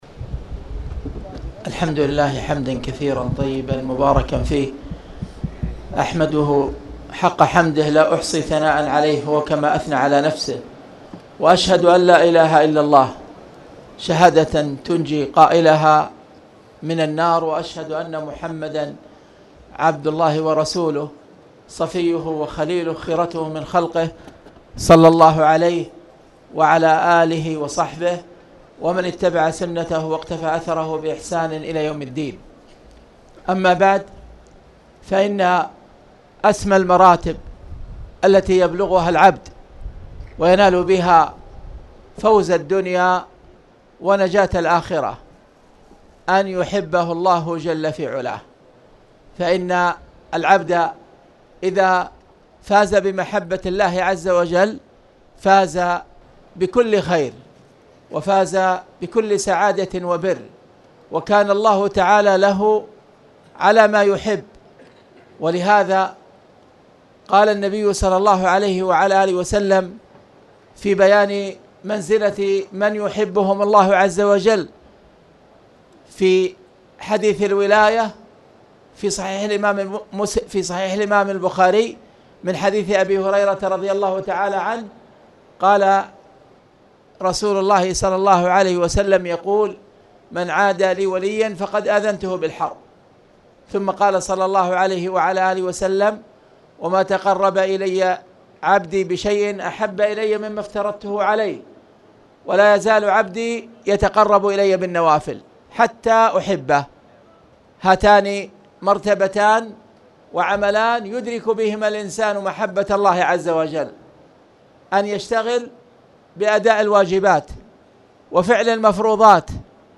تاريخ النشر ٤ جمادى الأولى ١٤٣٨ هـ المكان: المسجد الحرام الشيخ: خالد بن عبدالله المصلح خالد بن عبدالله المصلح باب الغسل وحكم الجنب